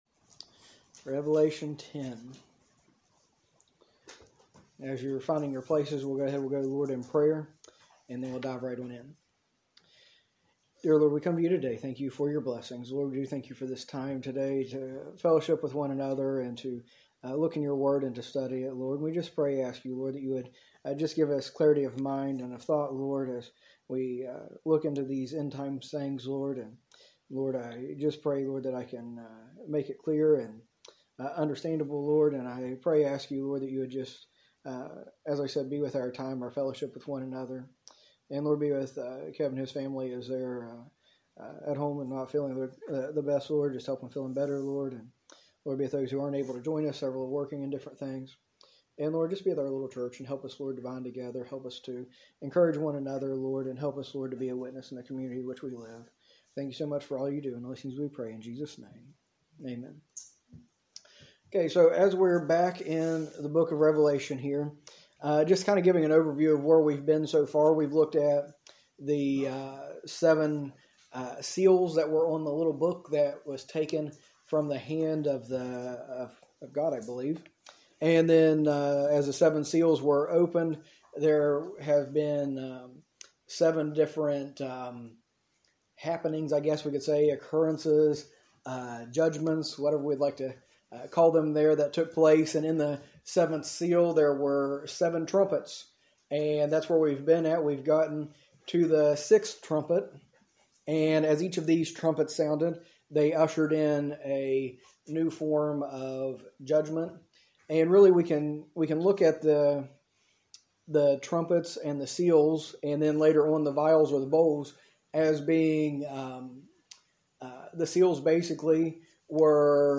A message from the series "Study on the End Times."